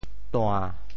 潮州府城POJ tuāⁿ 国际音标 [tũã]